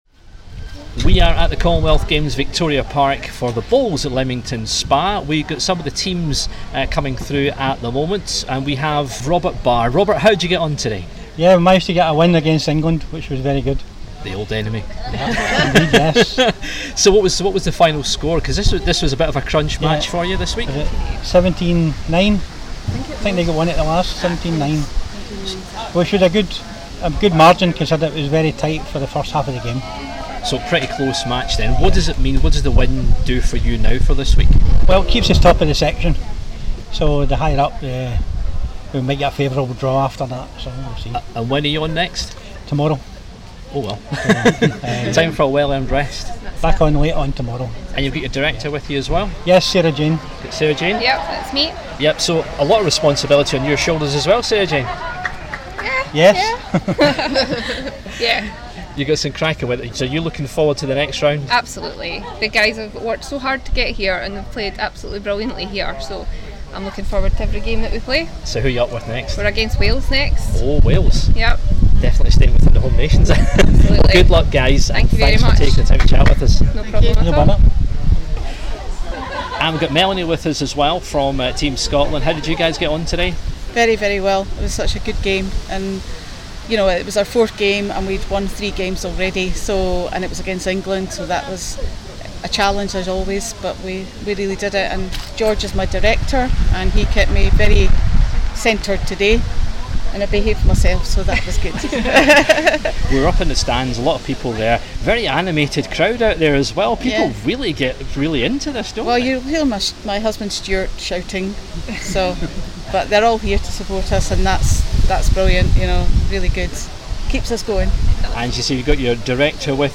caught up with the mixed pairs teams from England and Scotland